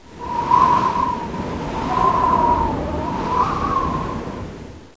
arcticwind.wav